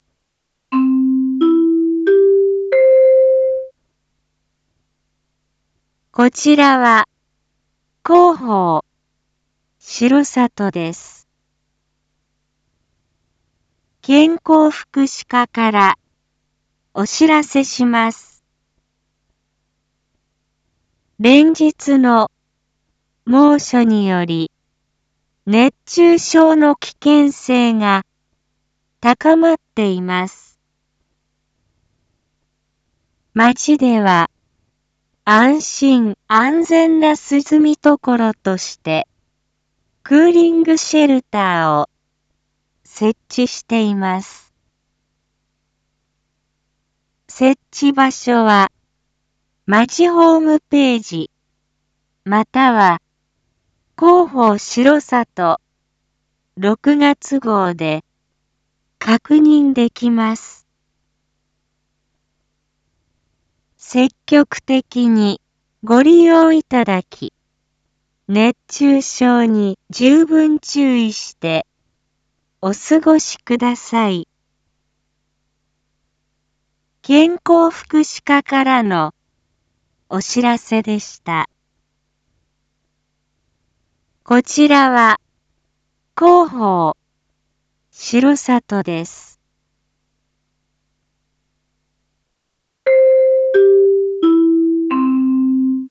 一般放送情報
Back Home 一般放送情報 音声放送 再生 一般放送情報 登録日時：2025-07-30 10:01:38 タイトル：R7クーリングシェルター インフォメーション：こちらは、広報しろさとです。